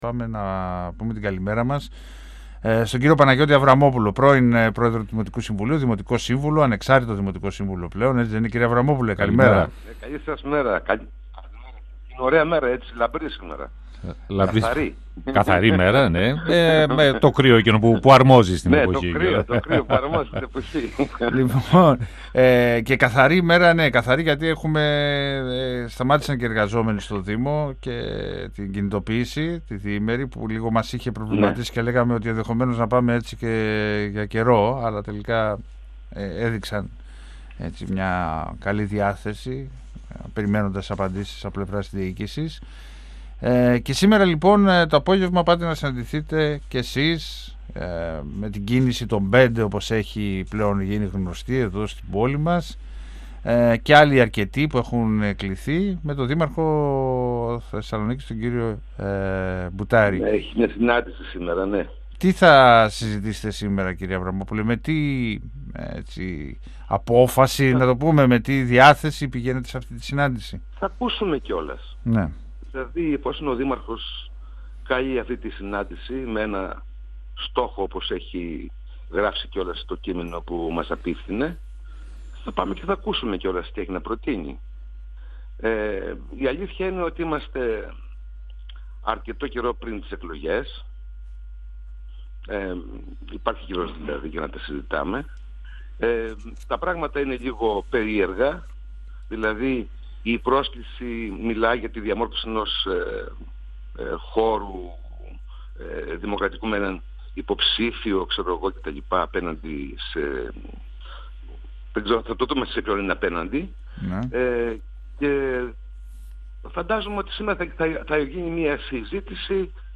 Η Κίνηση των 5 ανταποκρίνεται στο κάλεσμα του δημάρχου Θεσσαλονίκης Γιάννη Μπουτάρη και θα συμμετάσχει στη σημερινή συνάντηση εκπροσώπων σχημάτων ενόψει των δημοτικών εκλογών. Θα ακούσουμε τις προτάσεις του δημάρχου, ανέφερε ο πρώην πρόεδρος του δημοτικού συμβουλίου, Παναγιώτης Αβραμόπουλος, μιλώντας στον 102FM της ΕΡΤ3 αλλά εμφανίστηκε επιφυλακτικός όσον αφορά στο ενδεχόμενο συνεργασίας. Ο κ. Αβραμόπουλος επισήμανε ότι σκοπός της Κίνησης δεν είναι να αποτελέσει μια ακόμη παράταξη που θα διεκδικήσει την ψήφο των πολιτών αλλά να διαμορφωθούν συνεργασίες κι ένα σχέδιο για την πόλη.
Συνεντεύξεις